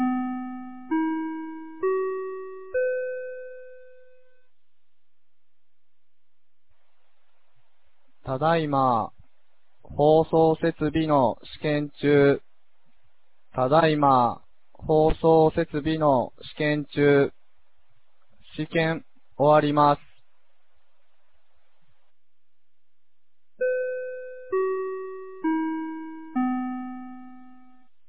2020年10月17日 16時02分に、由良町から全地区へ放送がありました。